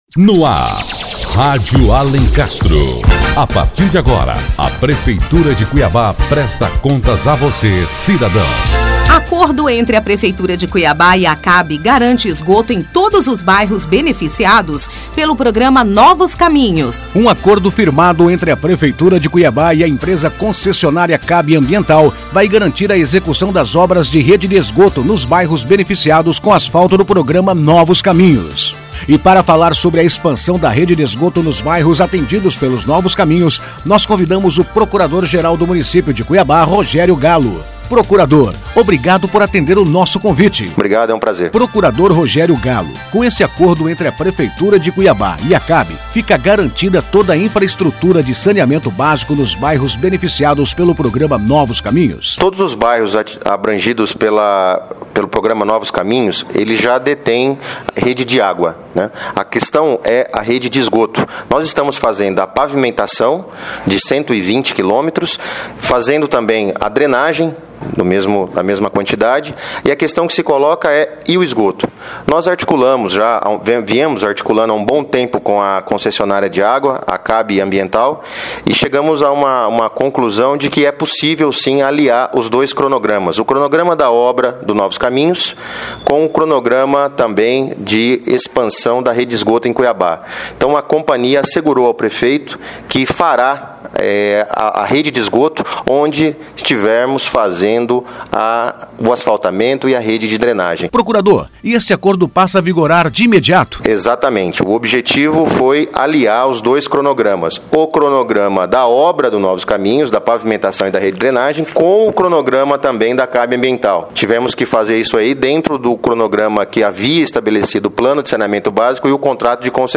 E para falar mais sobre este acordo, convidamos o Procurador Geral do Município, Rogério Gallo. Confira a entrevista.